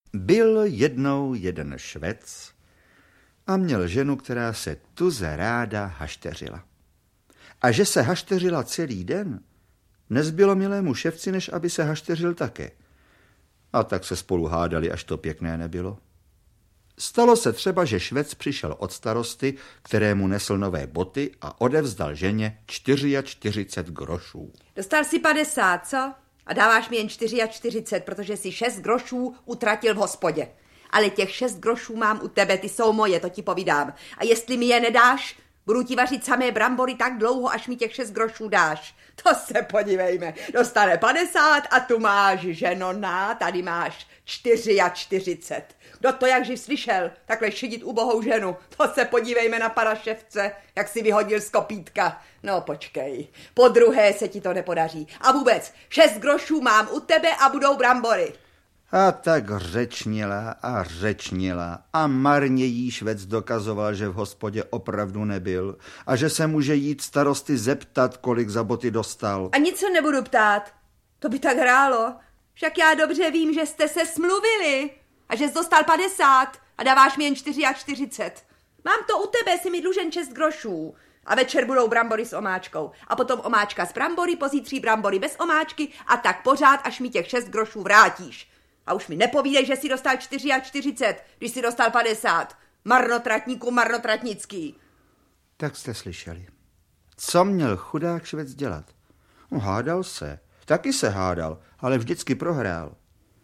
Valašské pohádky audiokniha
Ukázka z knihy
• InterpretJosef Somr, Miroslav Doležal